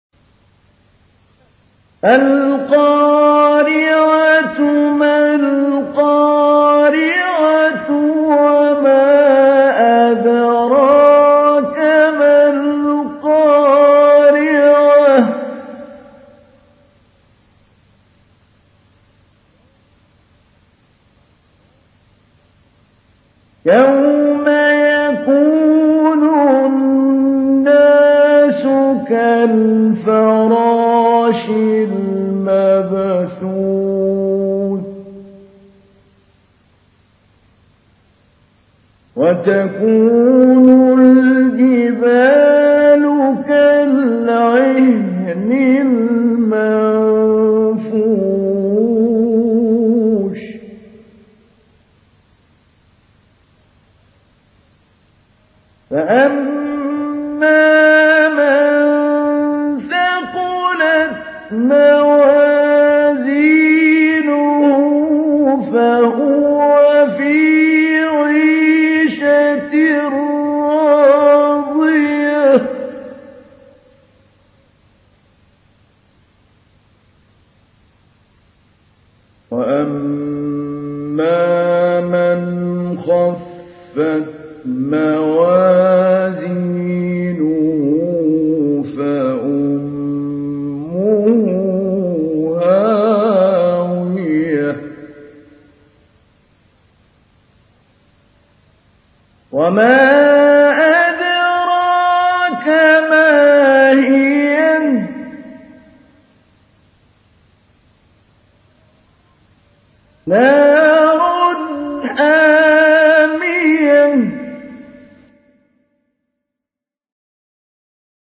تحميل : 101. سورة القارعة / القارئ محمود علي البنا / القرآن الكريم / موقع يا حسين